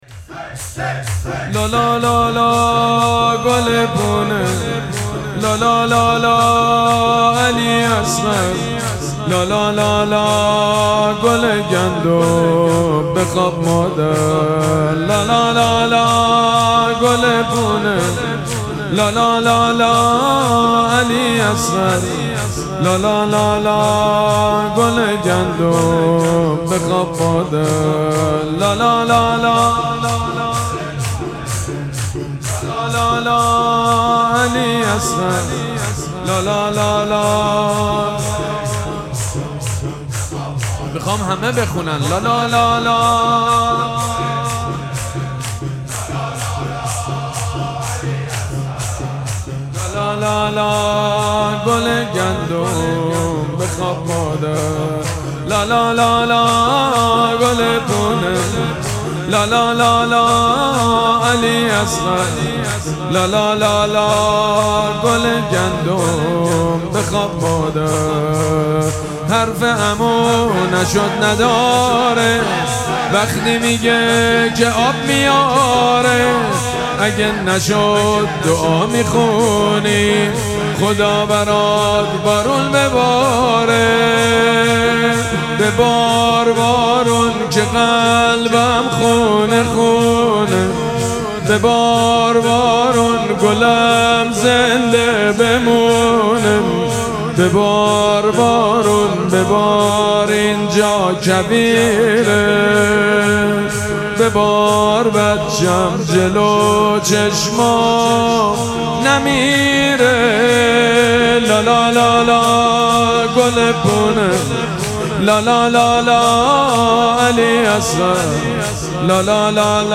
مراسم مناجات شب دوازدهم ماه مبارک رمضان
شور
مداح
حاج سید مجید بنی فاطمه